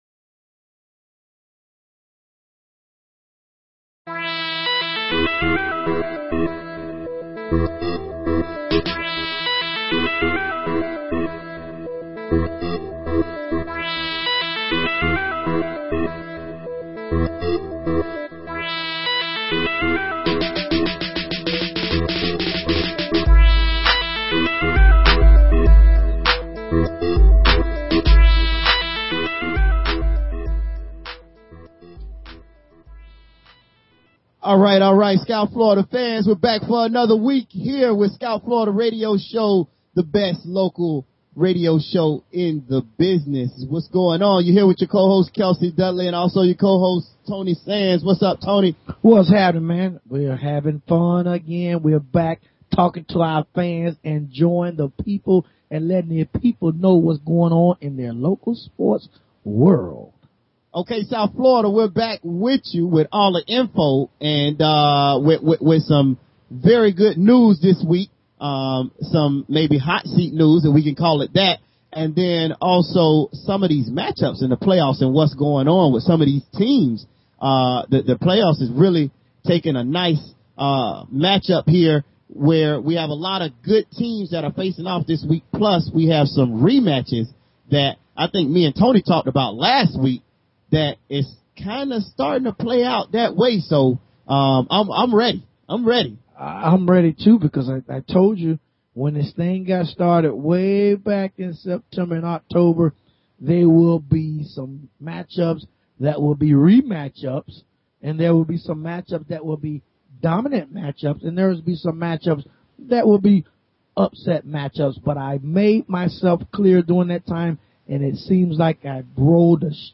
Talk Show Episode, Audio Podcast, Scout_Florida and Courtesy of BBS Radio on , show guests , about , categorized as